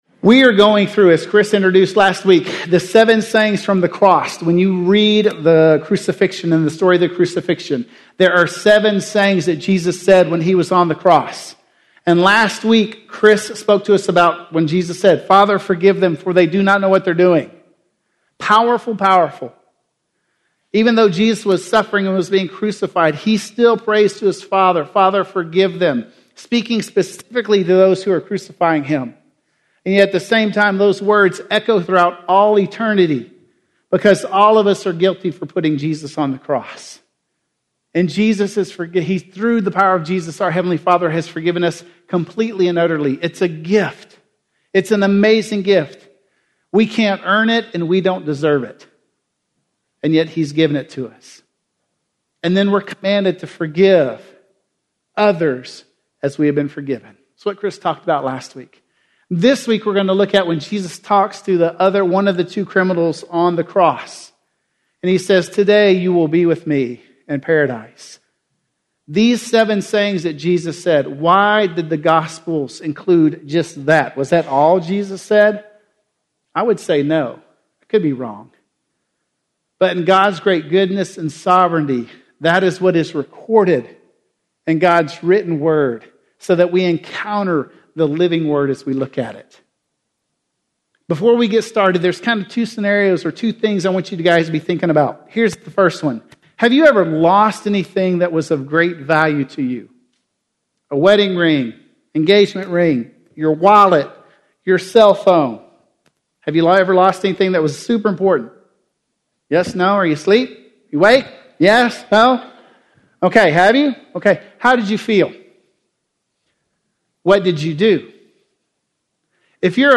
Today You Will Be With Me In Paradise - Sermon - Woodbine